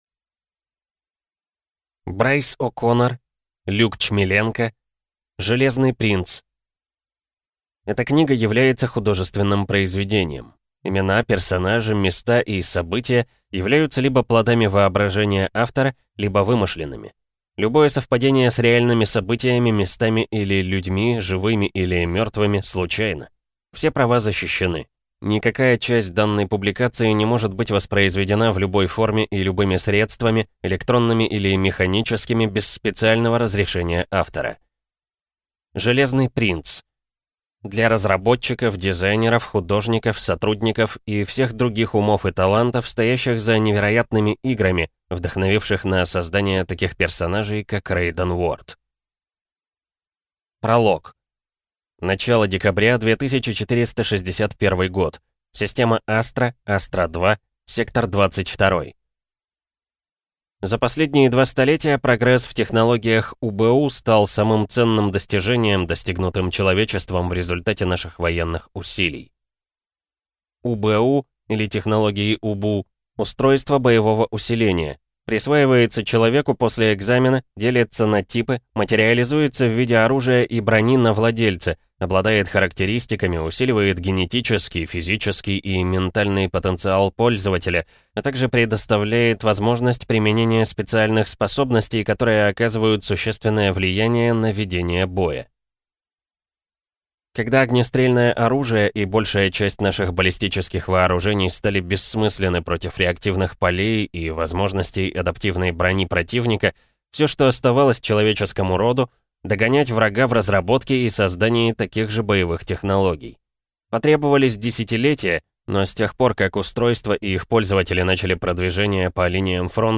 Аудиокнига Железный Принц | Библиотека аудиокниг